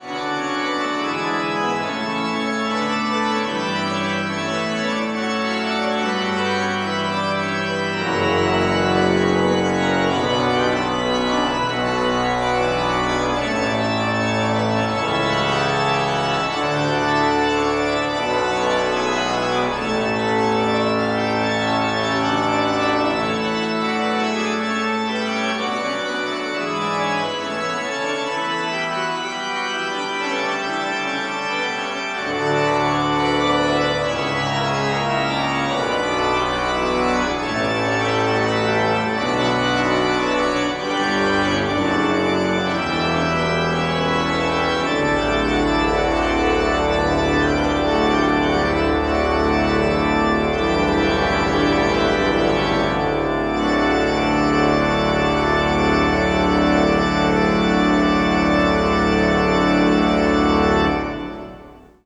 Tetrahedral Ambisonic Microphone
Organ Recital
Recorded December 12, 2009, in the Bates Recital Hall at the Butler School of Music of the University of Texas at Austin.